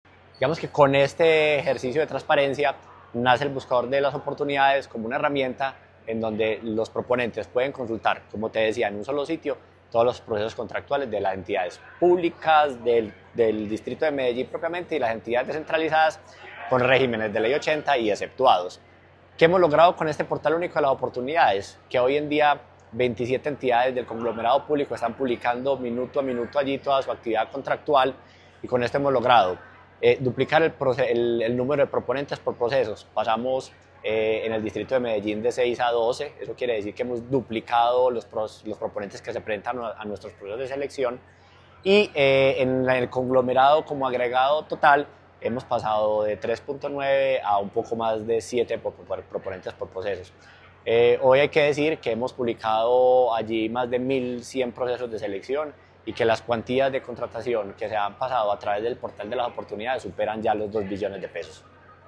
Declaraciones del secretario de Suministros y Servicios, Esteban Ramírez El Buscador de Oportunidades del Distrito de Medellín continúa consolidándose como una herramienta pionera y clave para la contratación pública en la ciudad.
Declaraciones-del-secretario-de-Suministros-y-Servicios-Esteban-Ramirez.mp3